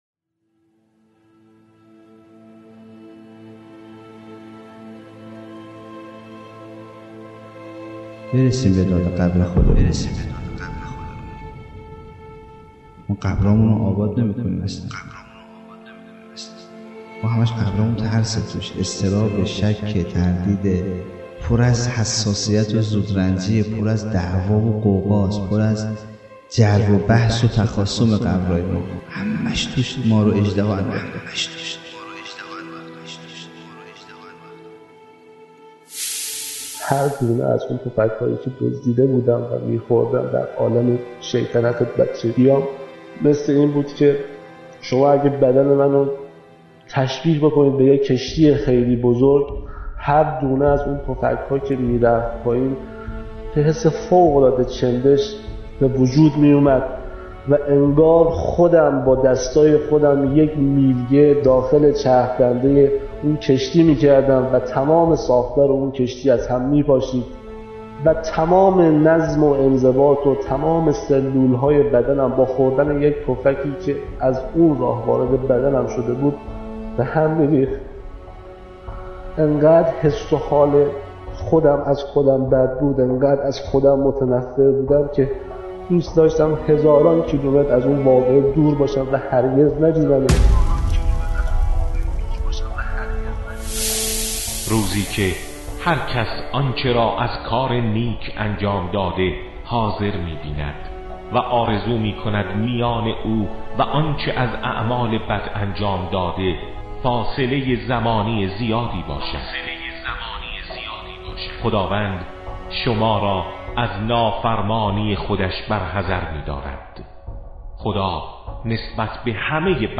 جلسات انسان شناسی